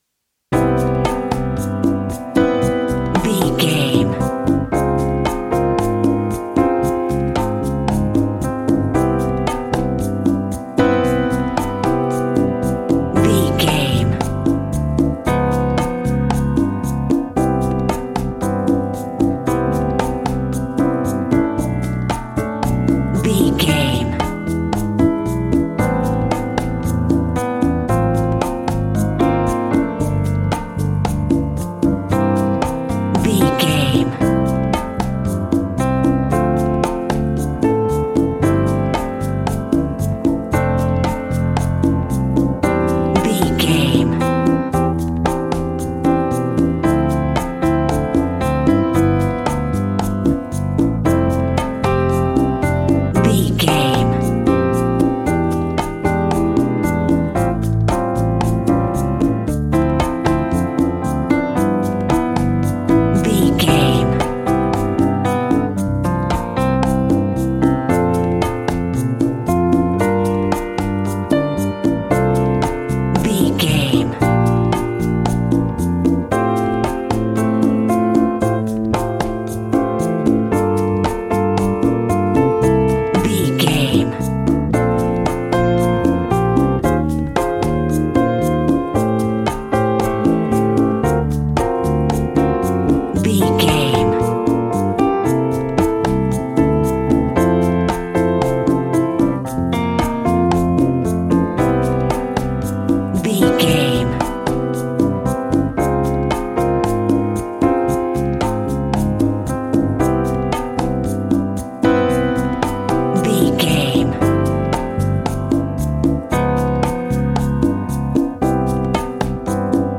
Ionian/Major
funky
energetic
romantic
percussion
electric guitar
acoustic guitar